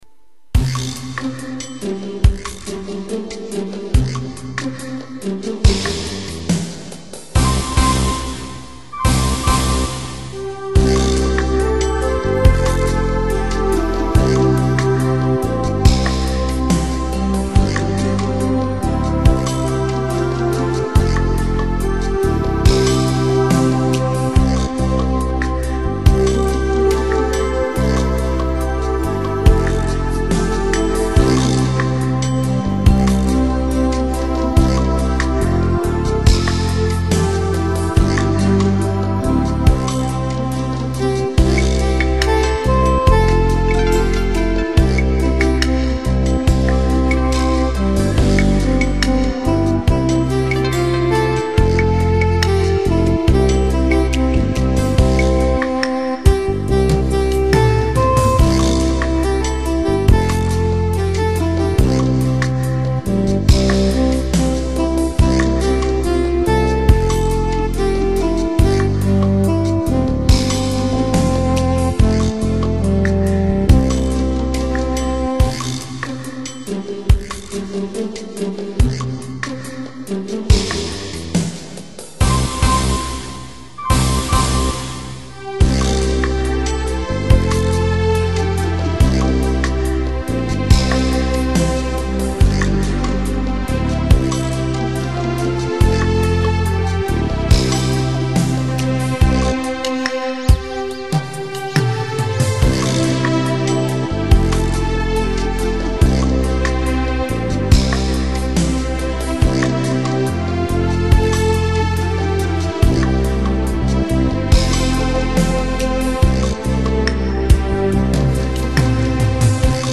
Nhạc không lời theo chủ đề